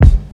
Kick (IN THE MOMENT).wav